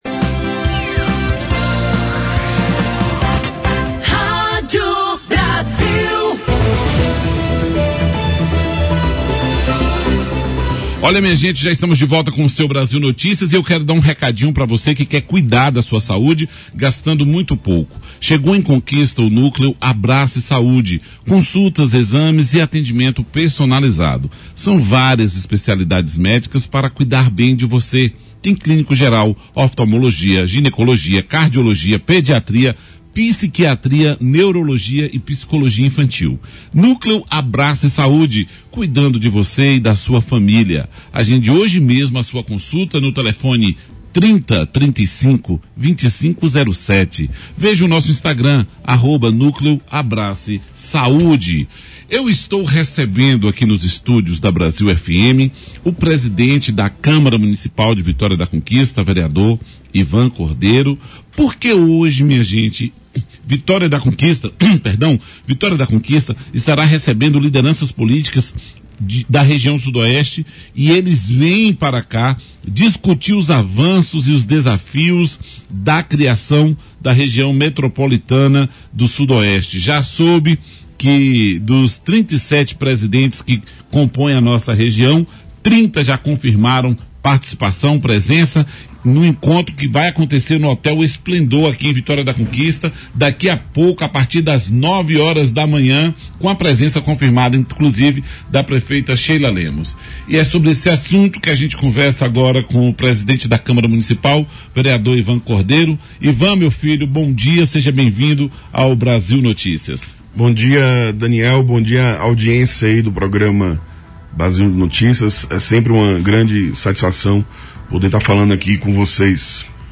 BLOG Entrevista | duplicação da BR-116, Hospital Metropolitano são prioridades em Vitória da Conquista